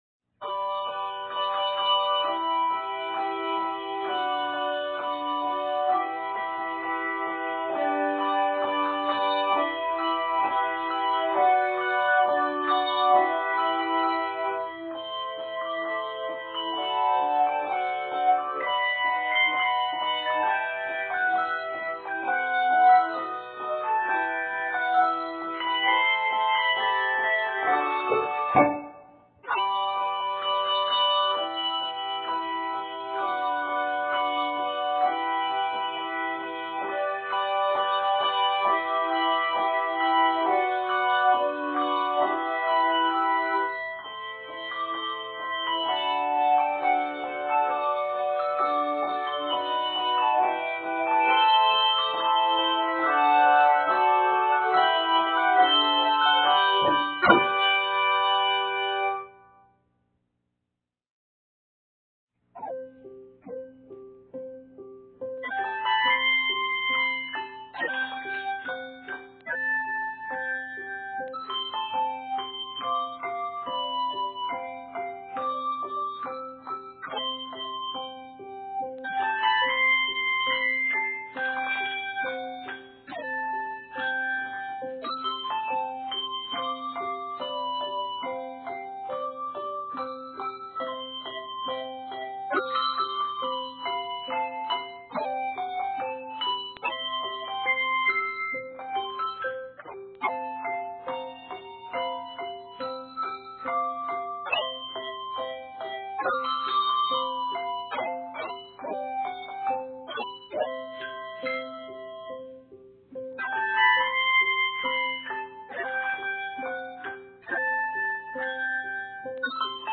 handbells or handchimes